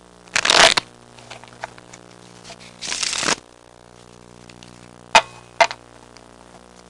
Dealing Cards (long) Sound Effect
Download a high-quality dealing cards (long) sound effect.
dealing-cards-long.mp3